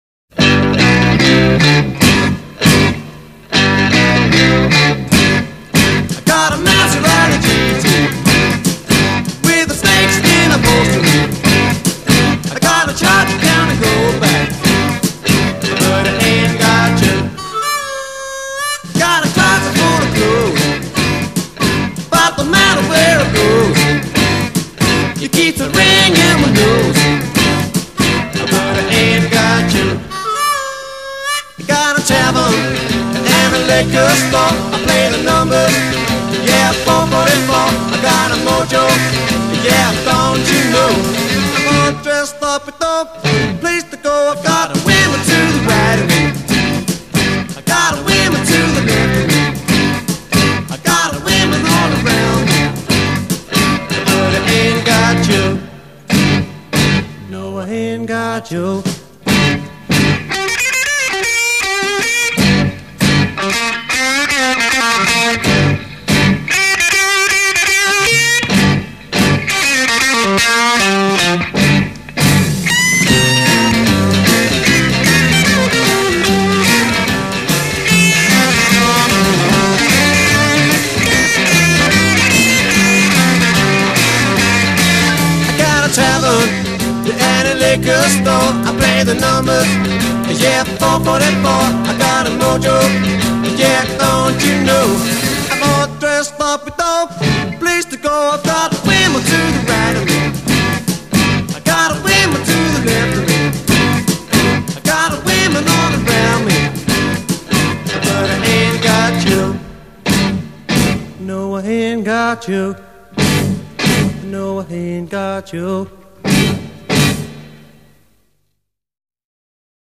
A Verse 0: 8 unison vocal solo a
A Verse : 8 as above; add harmonica insertions b
8 2 part harmony
A Verse : 16 guitar solo
British Blues